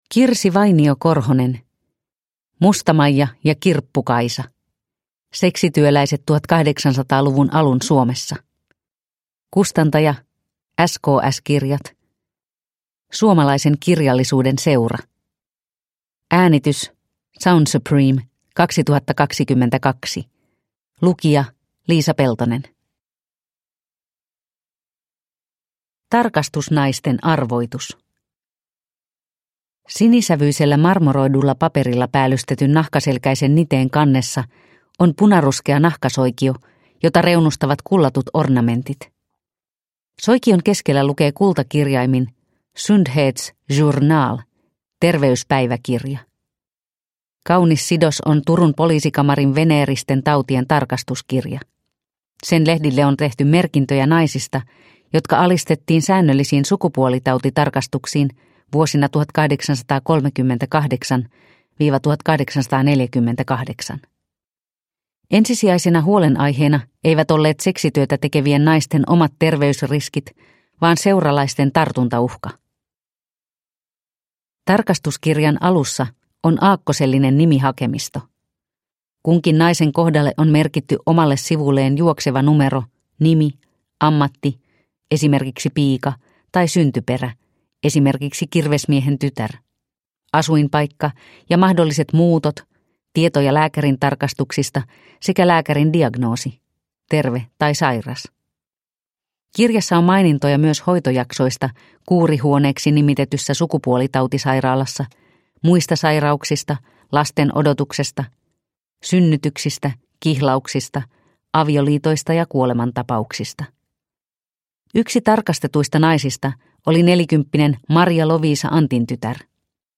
Musta-Maija ja Kirppu-Kaisa – Ljudbok – Laddas ner